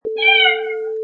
Cat Meow 3.wav
A cat meows
Product Info: 48k 24bit Stereo
Category: Animals / Cats
Try preview above (pink tone added for copyright).
Cat_Meow_3.mp3